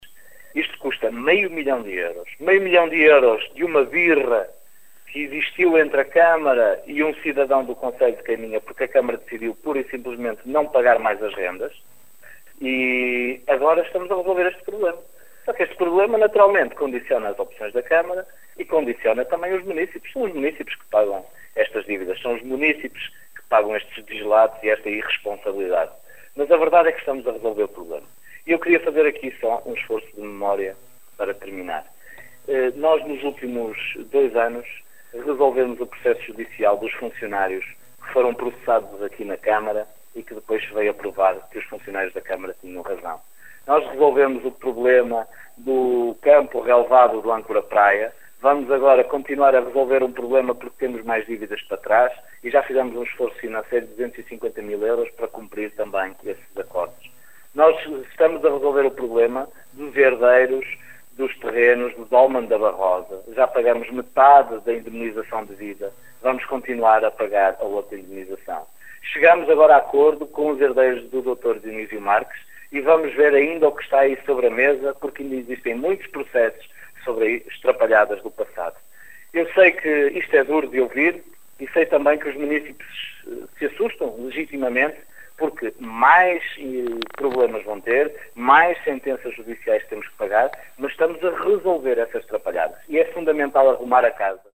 O socialista diz que este é mais um exemplo da “pesada heraça” que este executivo recebeu nos últimos anos. “Um legado que cai em cima da câmara Municipal mas, sobretudo, dos munícipes”, salienta Miguel Alves